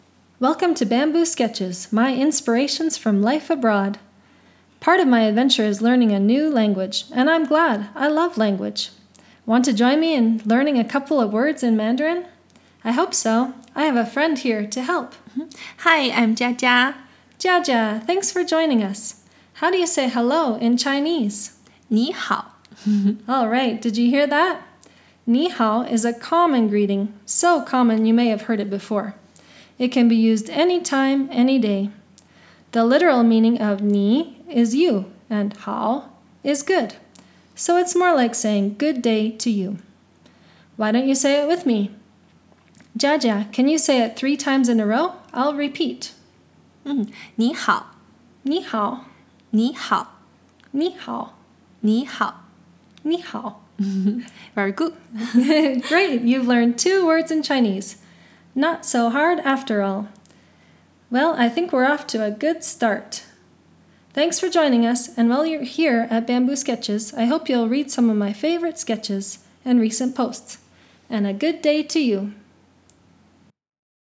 It’s wonderful to hear your pleasant voice. And your friend’s!